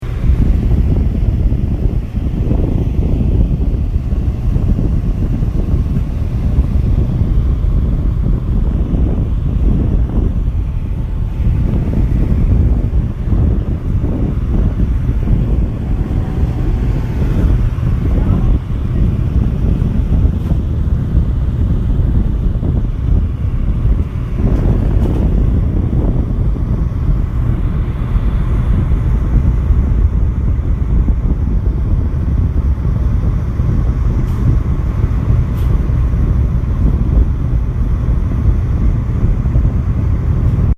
Field Recording #1
The industrial radiator that heats the lobby of Constitution Hall.
2.) radiator hum, refrigerator thrum
Radiator-Field-Recording-1.mp3